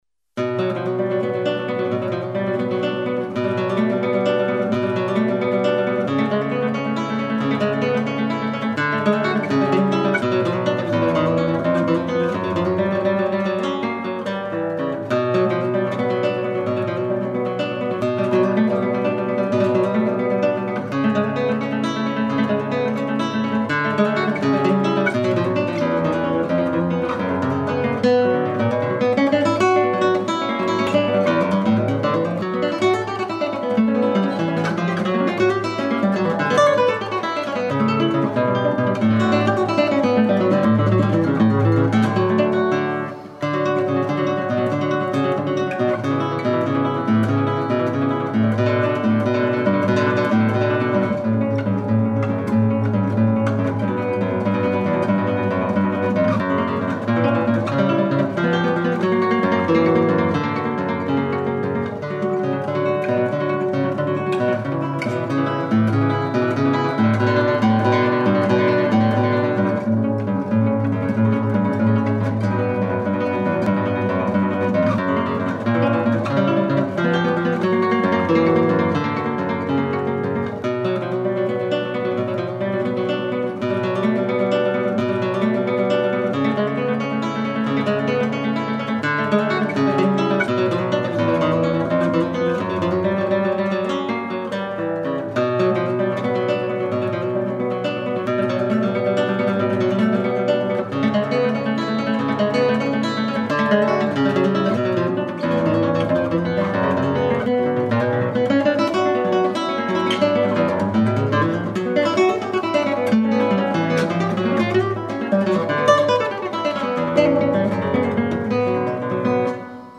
Solo Guitar / Late Romantic / Folkloric.